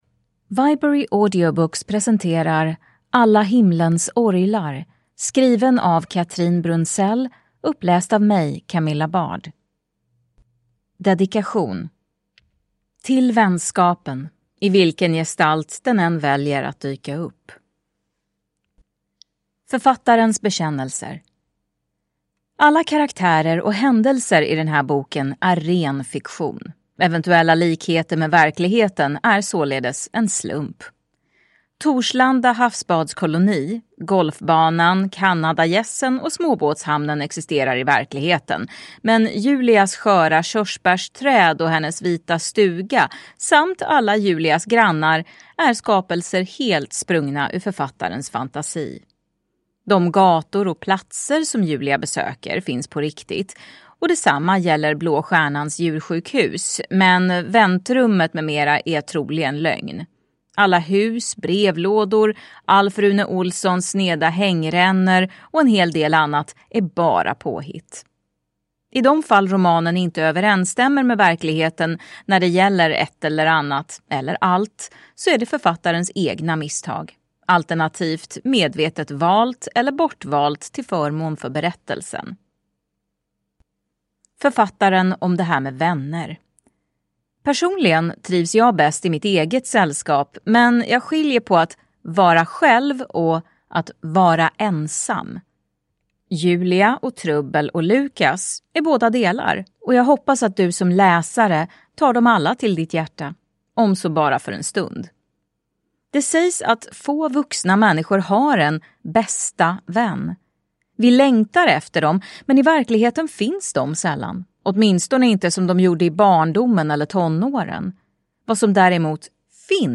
Alla himlens orglar (ljudbok) av Cathrine Brunzell